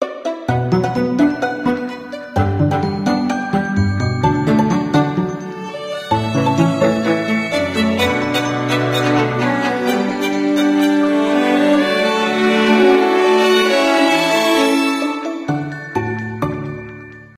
скрипка , без слов , мелодичные
инструментальные